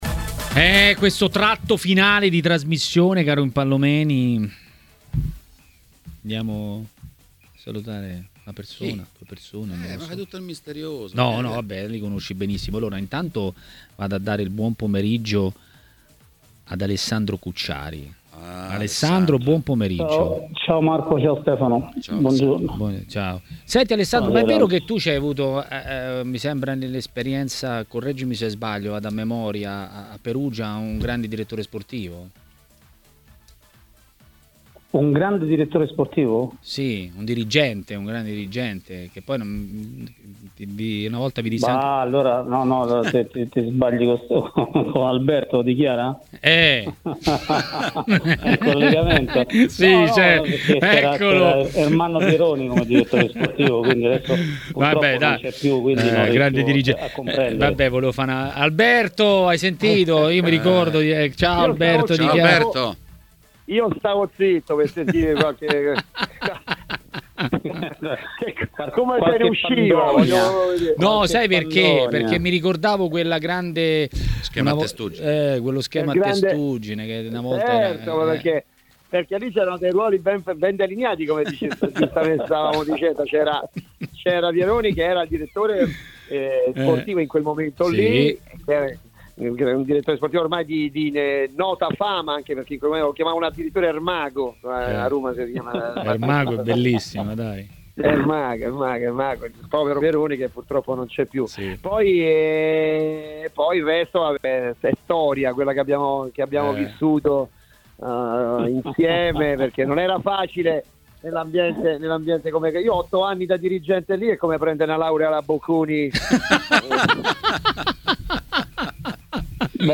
Ospite di Maracanà, trasmissione di TMW Radio, è stato l'ex calciatore Alberto Di Chiara.